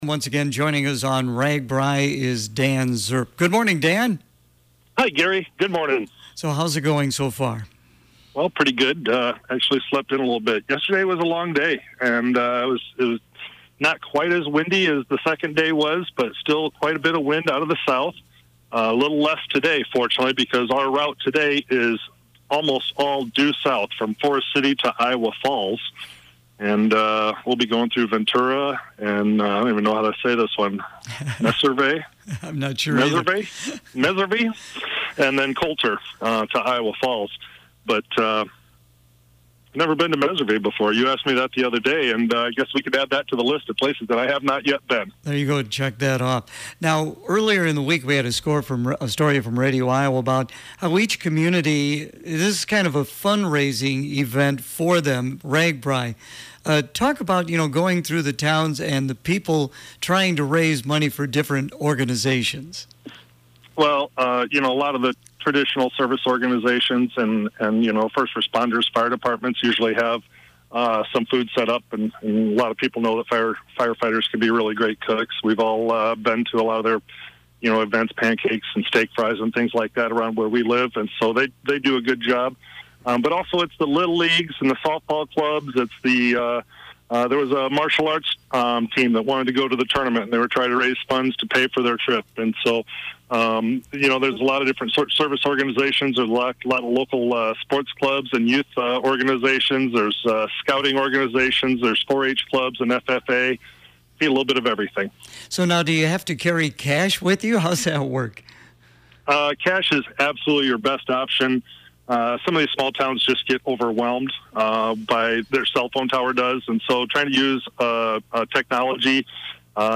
Wednesday Ragbrai Report with Dan Srp
Clinton County Supervisor Dan Srp is once again riding on Ragbrai this year and is keeping listeners informed about this unique Iowa event.
If you missed it on the air, todays report is posted below.